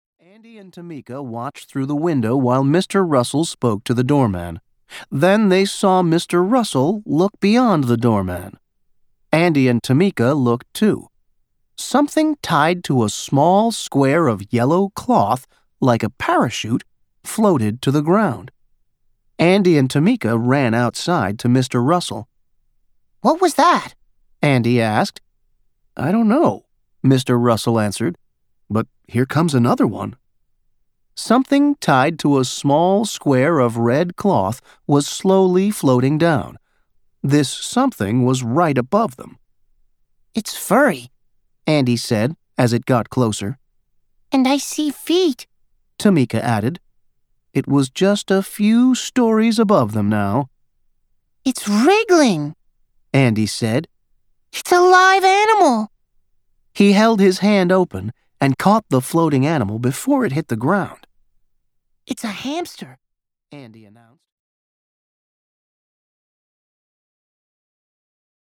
Middle Grade Audiobooks